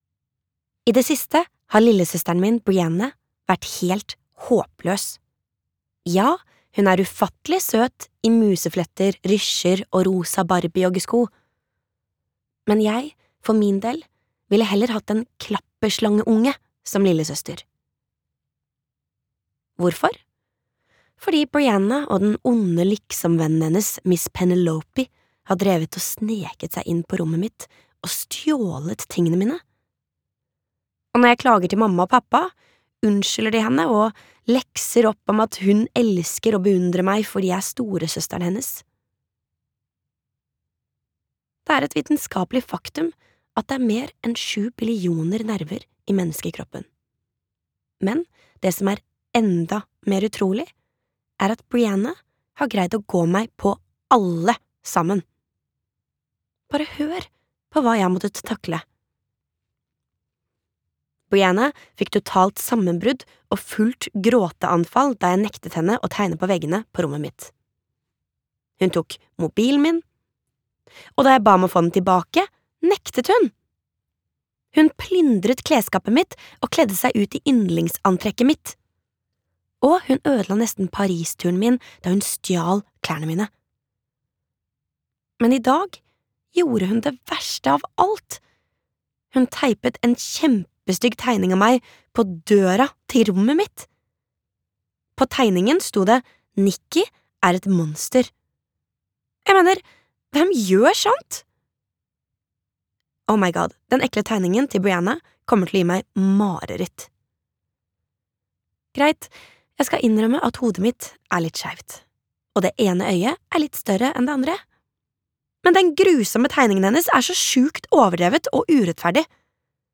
Nedtegnelser fra en rampete lillesøster (lydbok) av Rachel Renée Russell